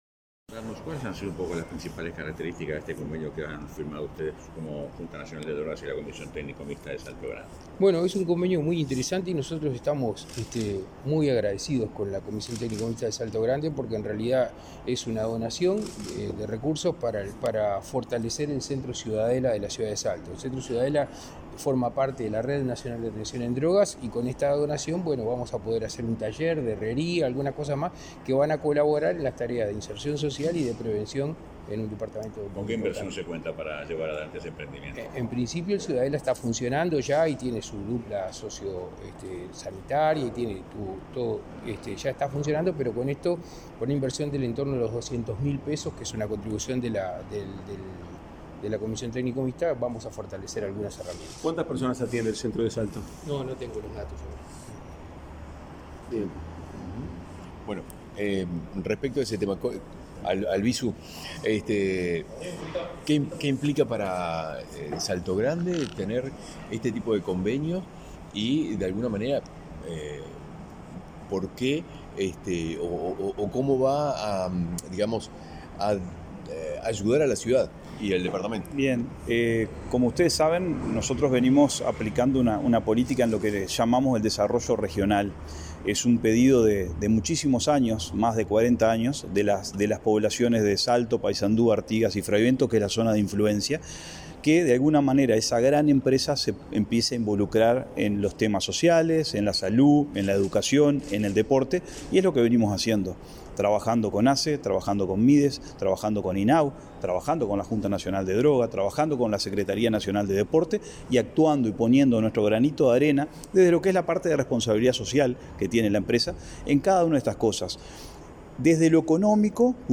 Declaraciones de prensa del secretario de la JND, Daniel Radío, y del presidente de la Comisión Técnica Mixta de Salto Grande, Carlos Albisu
La Junta Nacional de Drogas (JND) firmó un convenio con la Comisión Técnica Mixta de Salto Grande para reforzar la atención en el dispositivo Ciudadela de esa ciudad. El secretario general de la JND, Daniel Radío, y el presidente de la comisión, Carlos Albisu, efectuaron declaraciones a la prensa, en las que explicaron los alcances del acuerdo.